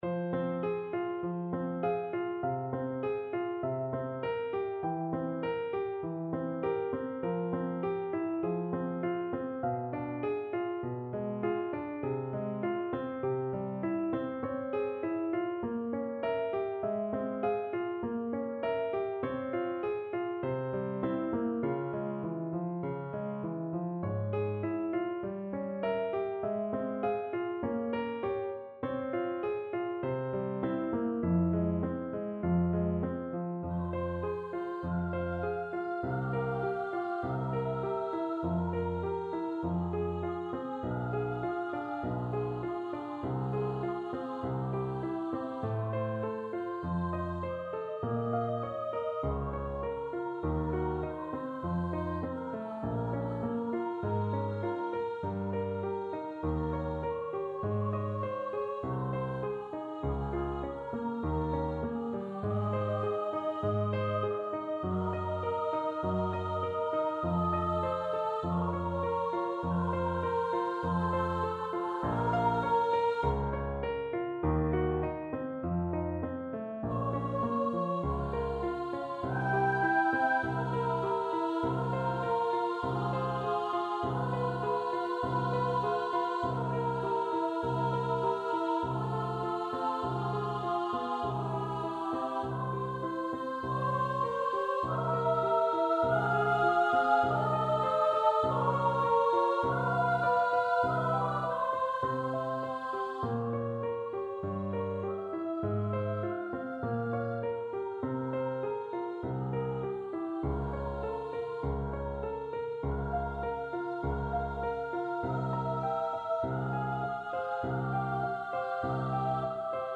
Free Sheet music for Choir (SATB)
Soprano VoiceAlto Voice
4/4 (View more 4/4 Music)
F minor (Sounding Pitch) (View more F minor Music for Choir )
Largo assai = c.50
Classical (View more Classical Choir Music)